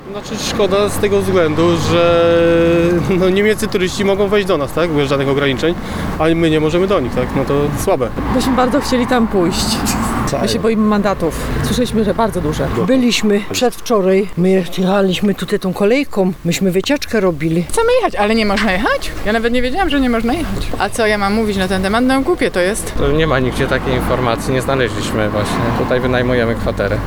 Nie chcemy dostać mandatu – mówi jedna z turystek.